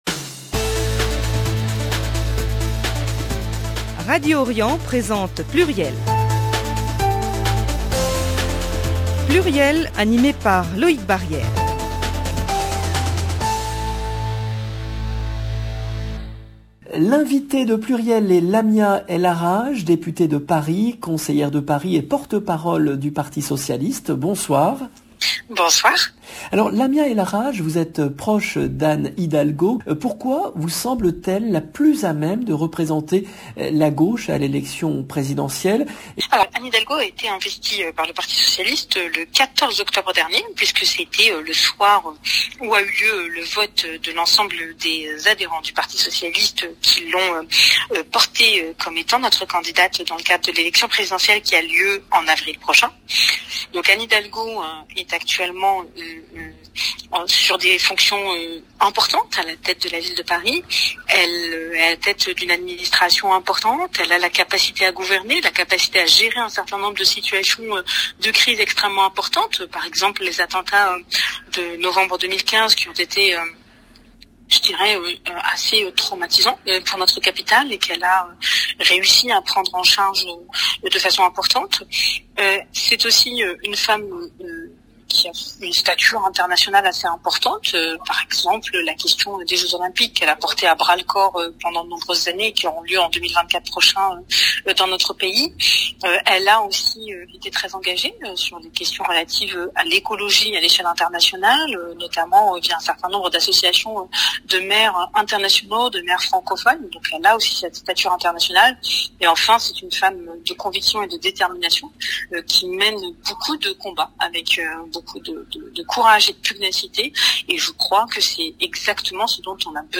PLURIEL, émission diffusée le vendredi 22 octobre 2021
L’invitée de PLURIEL est Lamia El Aaraje , députée de Paris, conseillère de Paris et porte-parole du Parti Socialiste Quels seront les axes de la campagne d'Anne Hidalgo ? Comment sortir des débats identitaires imposés par Eric Zemmour et les commentateurs politiques ?